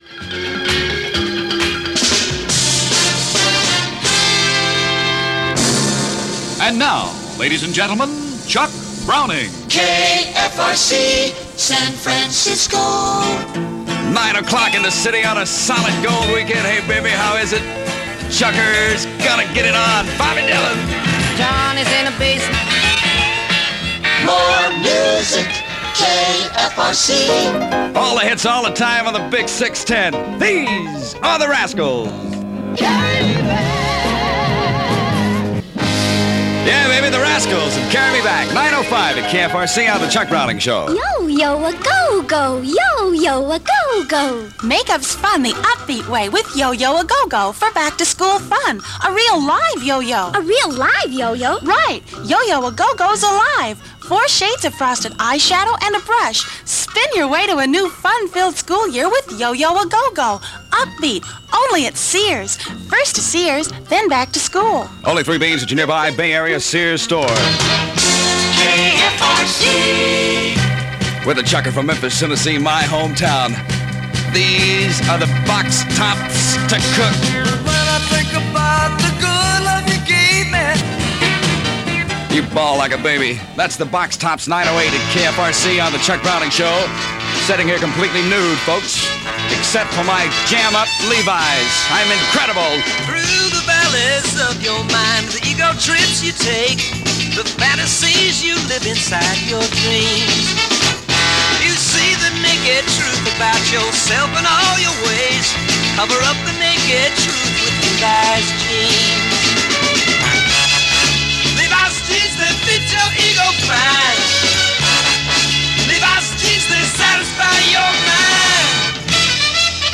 Aircheck of the Week